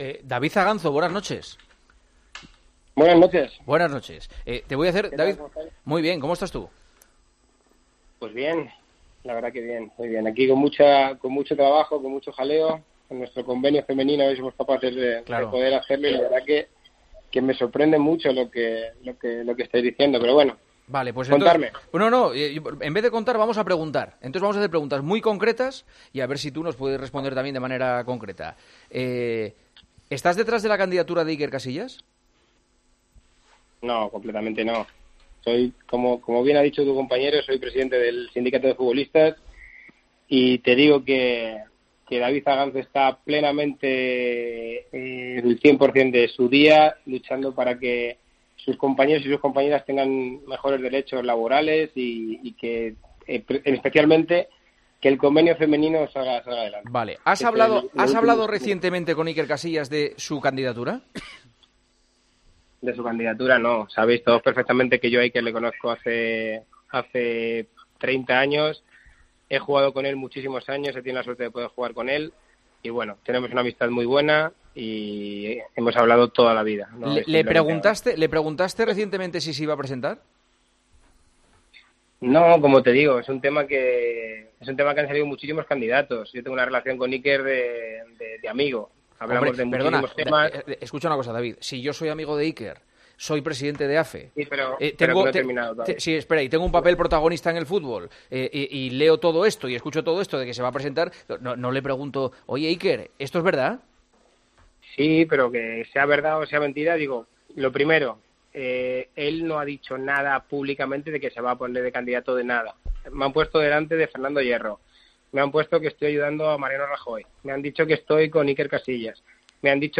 AUDIO: Entrevistamos en El Partidazo de COPE a David Aganzo, presidente de la Asociación de Futbolistas Españoles.